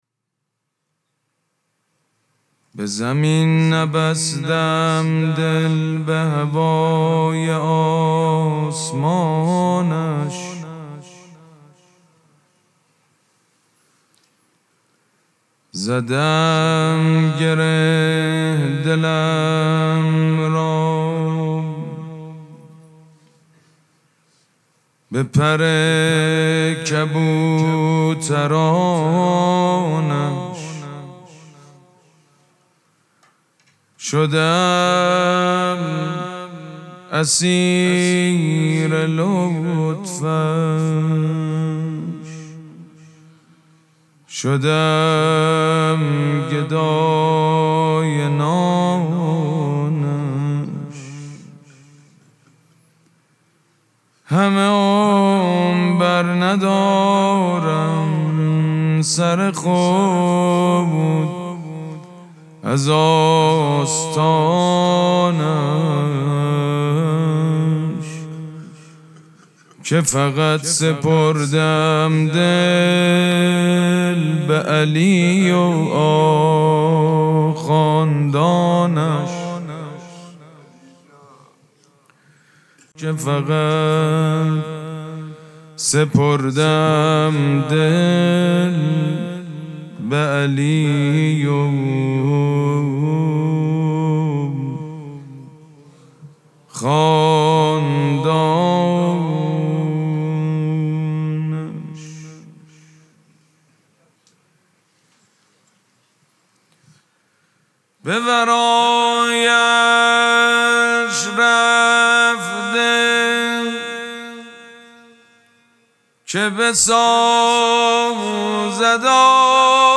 مراسم مناجات شب بیستم ماه مبارک رمضان پنجشنبه‌‌ ۳۰ اسفند ماه ۱۴۰۳ | ۱۹ رمضان ۱۴۴۶ حسینیه ریحانه الحسین سلام الله علیها
سبک اثــر مدح مداح حاج سید مجید بنی فاطمه